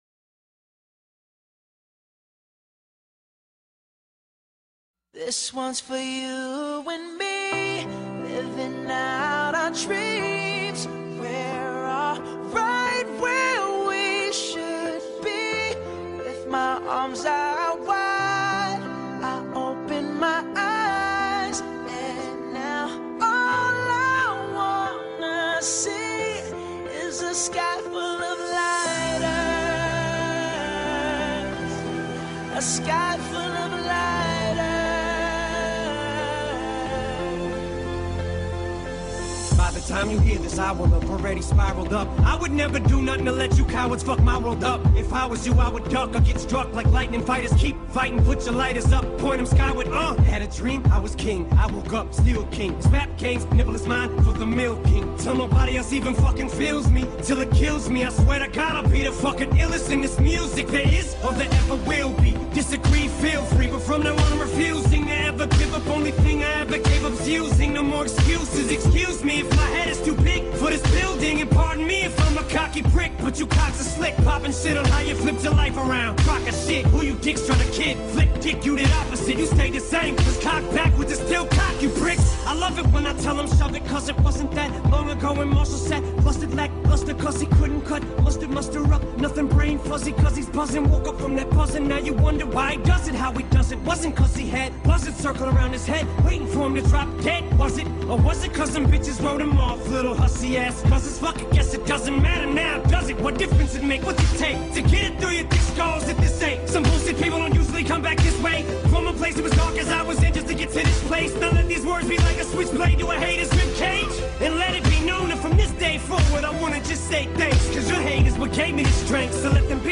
Категория: Зарубежный рэп, хип-хоп